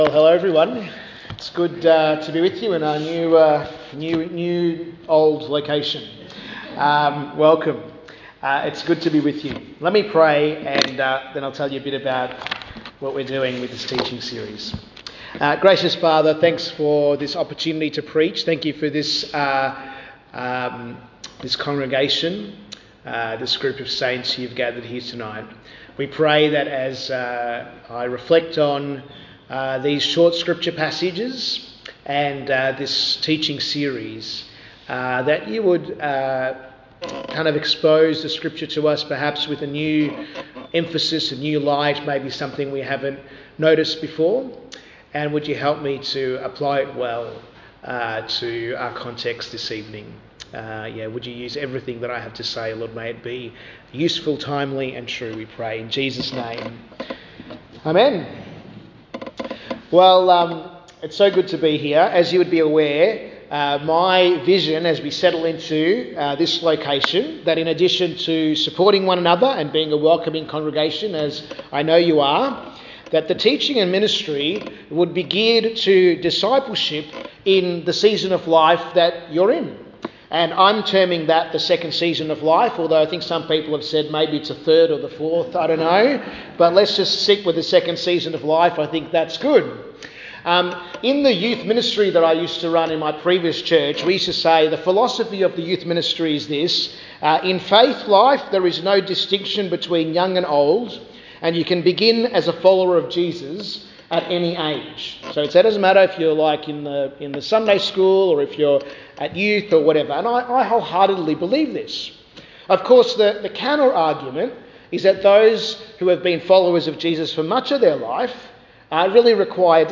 Sermons | St Hilary's Anglican Church